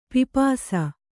♪ pipāsa